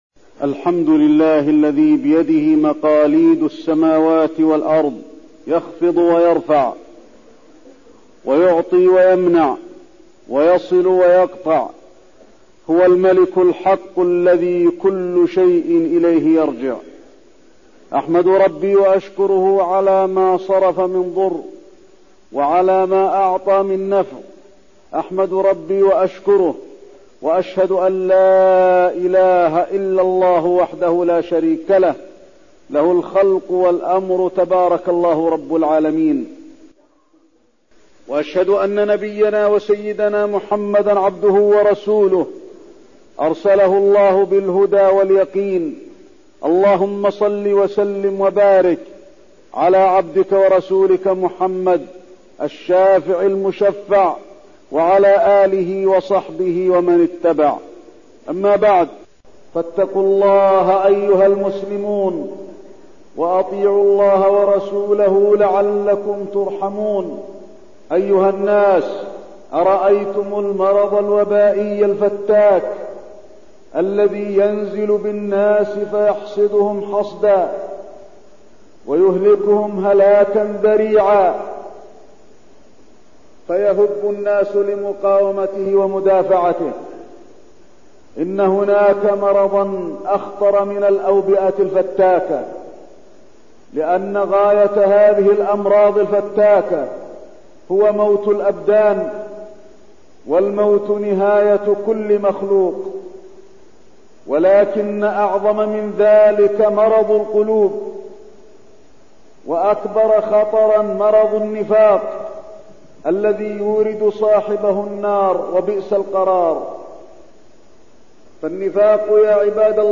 تاريخ النشر ٢٤ محرم ١٤١٣ هـ المكان: المسجد النبوي الشيخ: فضيلة الشيخ د. علي بن عبدالرحمن الحذيفي فضيلة الشيخ د. علي بن عبدالرحمن الحذيفي النفاق